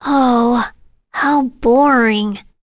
Leo-vox-get-01_b.wav